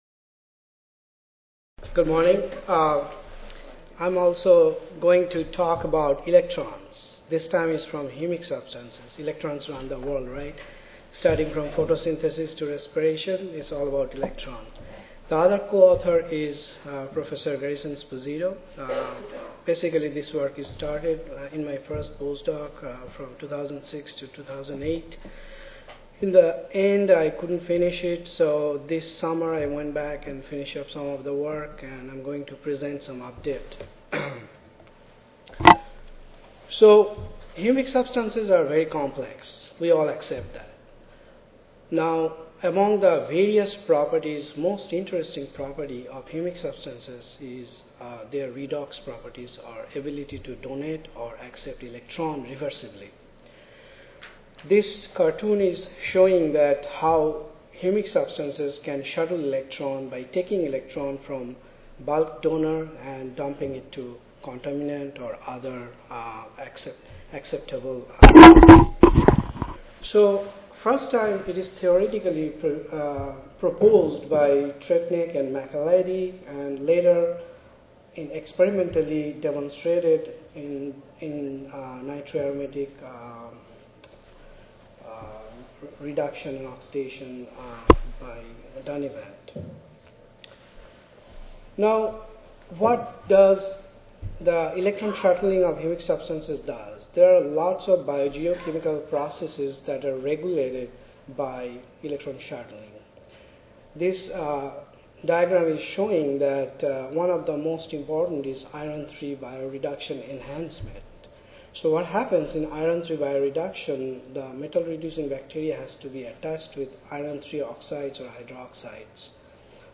University of California-Berkeley Audio File Recorded Presentation